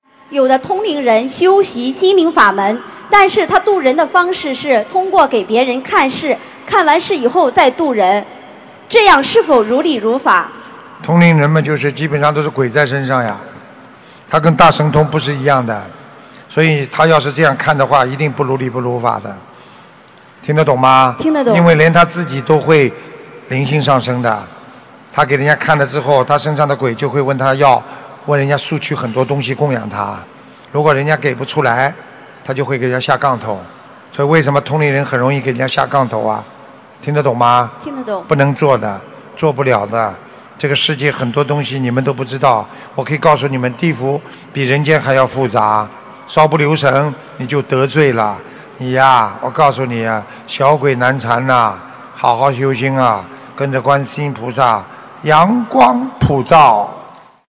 通灵人用通灵的方法度人学佛是否如理如法——弟子提问 师父回答--2017年马来西亚吉隆坡弘法解答会（2）博客 2018-04-03 &nbsp ...